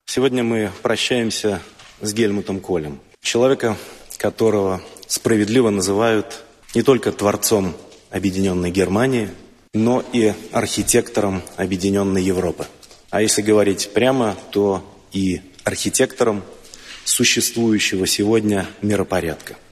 Говорит премьер-министр России Дмитрий Медведев